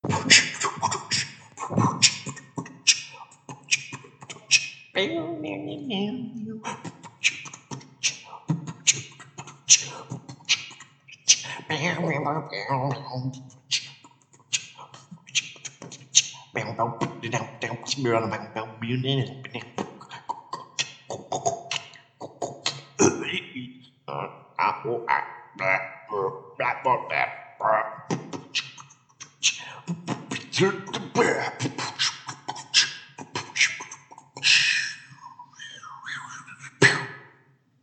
Beatbox.mp3